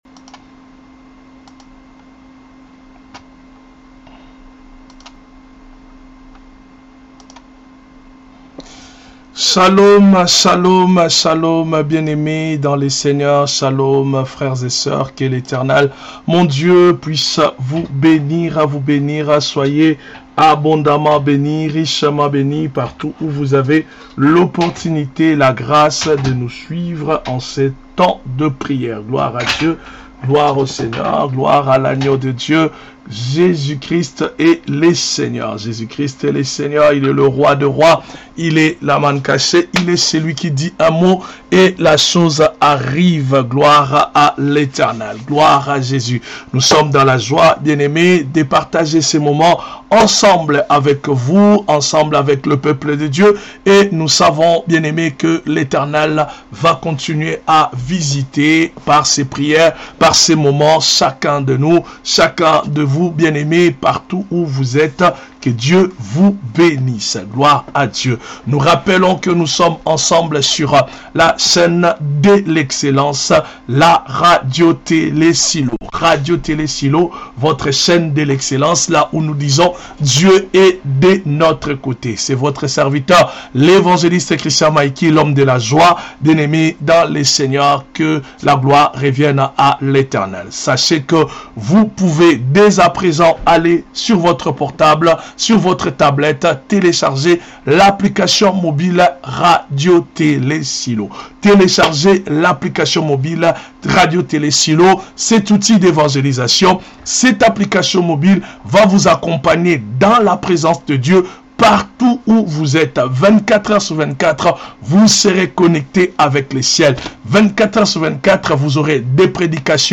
Un temps d'intercession intensive